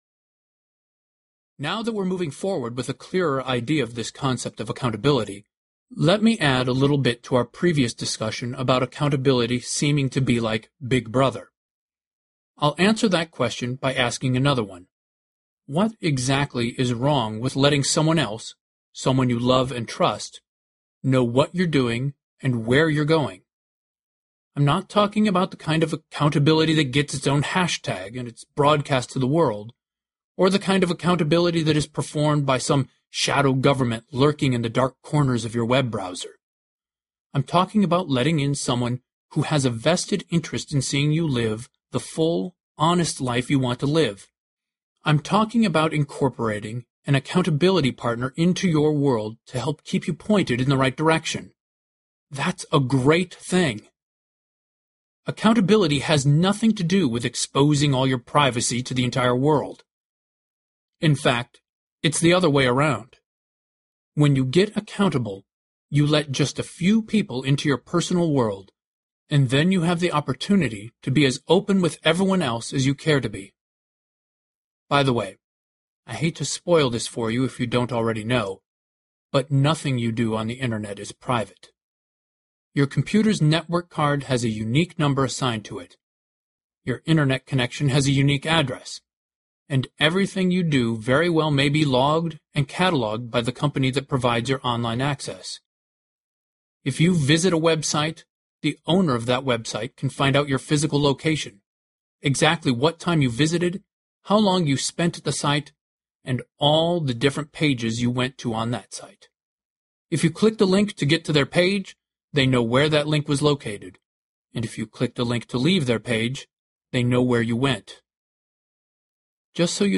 Narrator
5.1 Hrs. – Unabridged